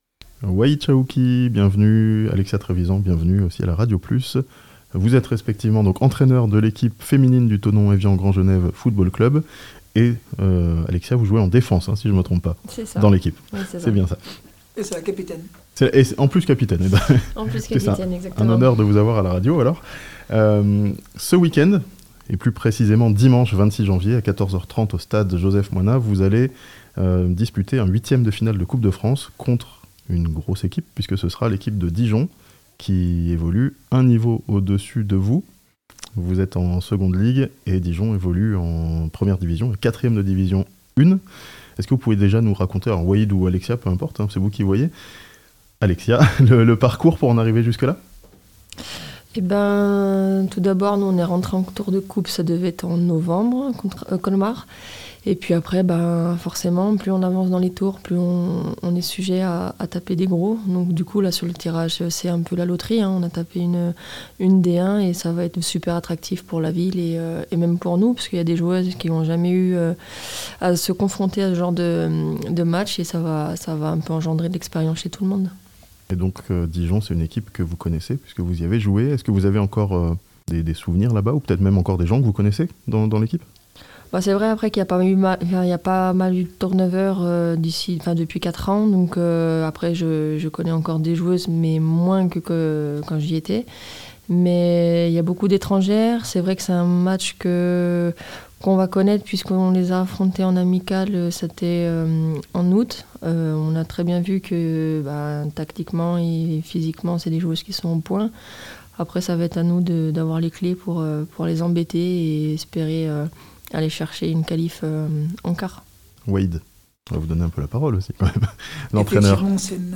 Football féminin: Thonon Evian en 8èmes de finale de coupe de France dimanche 26 janvier (interview)